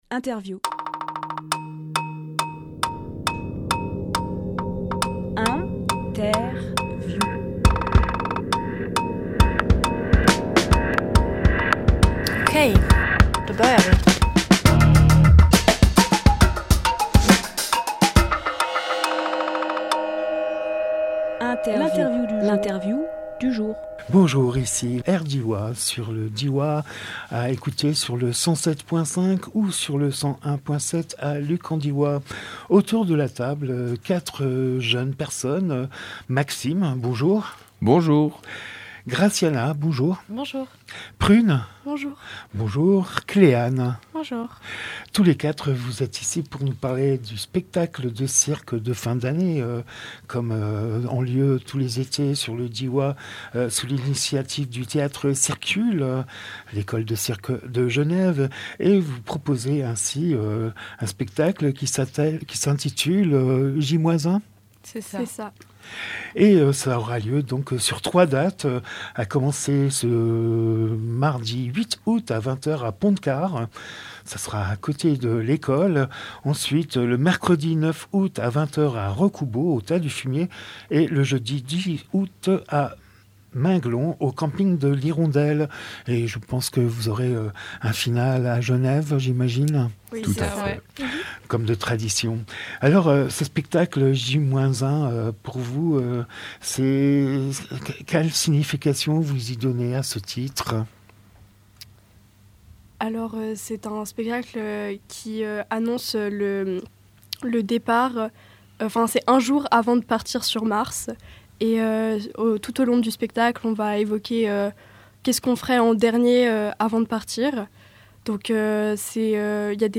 Emission - Interview J-1 par le Théâtre-cirqule Publié le 7 août 2023 Partager sur…
07.08.23 Lieu : Studio RDWA Durée